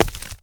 PickAxe.wav